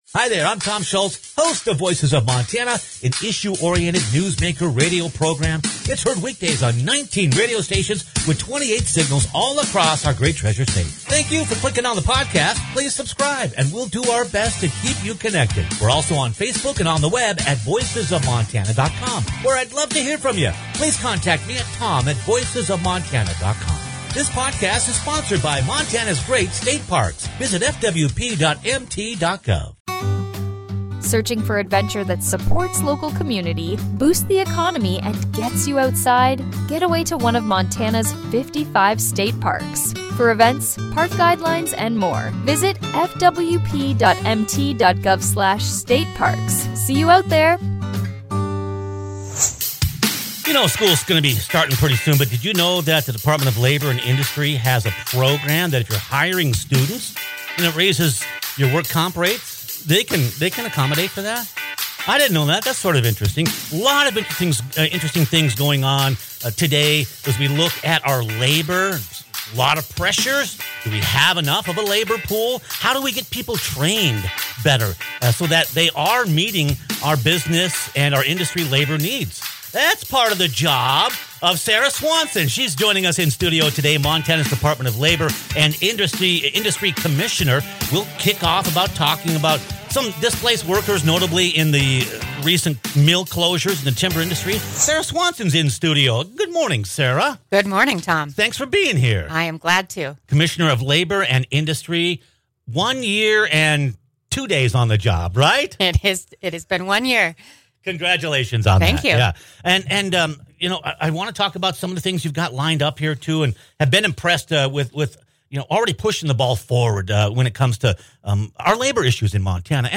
How does Montana help displaced mill workers? The head of Montana’s Department of Labor and Industry, Sarah Swanson, joined us in-studio to talk about Montana’s labor market and how the agency is helping train displaced workers and working with industry leaders to help prepare a valuable workforce.